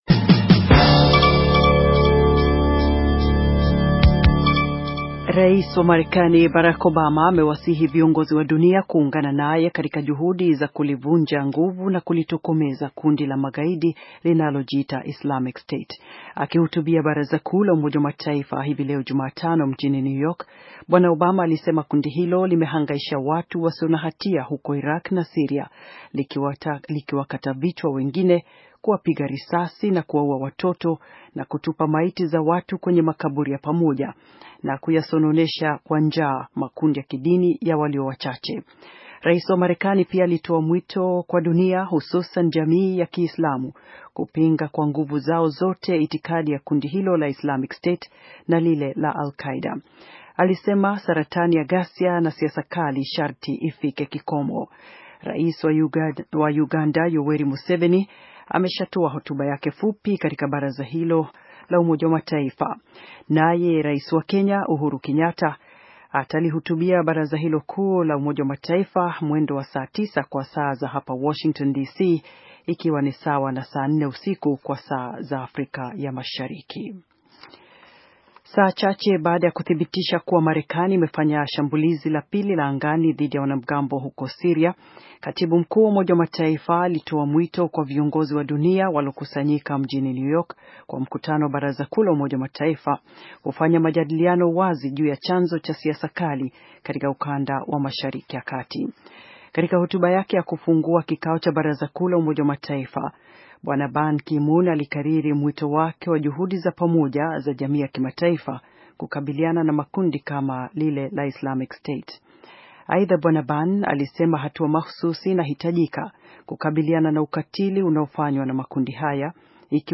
Taarifa ya habari - 5:14